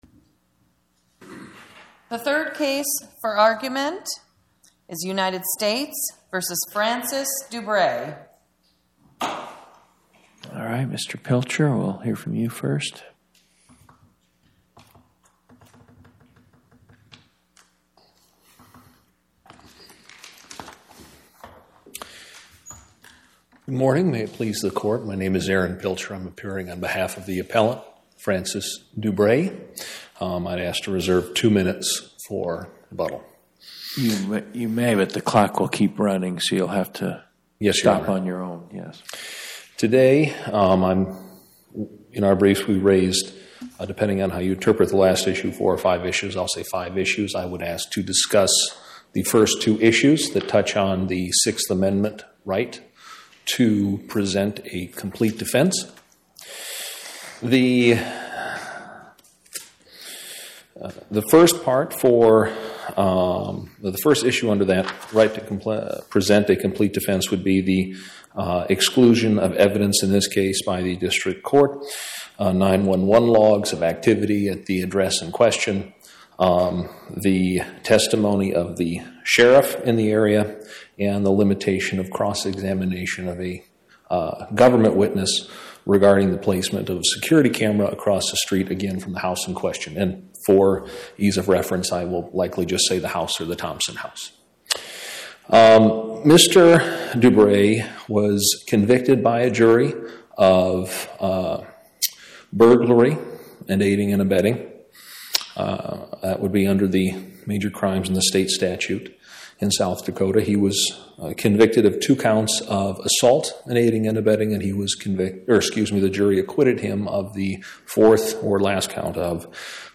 Oral argument argued before the Eighth Circuit U.S. Court of Appeals on or about 02/13/2026